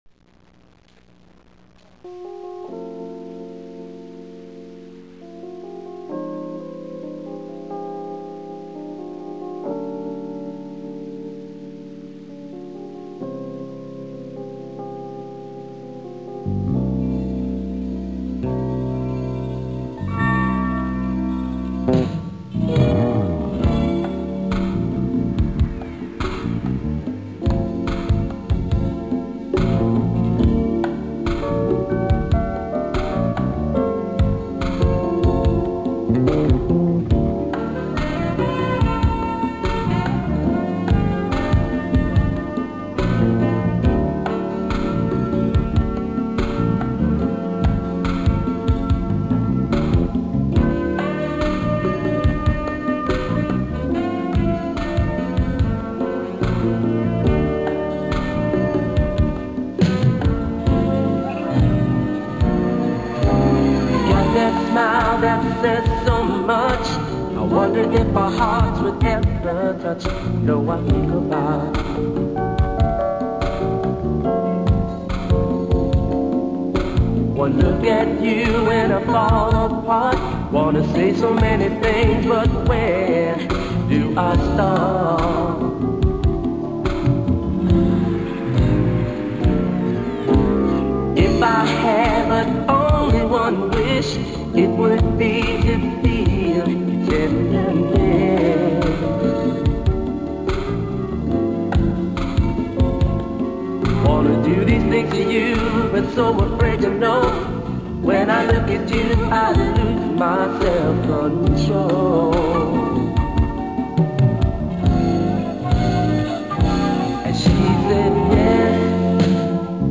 My drum parts
Dance/R&B/Disco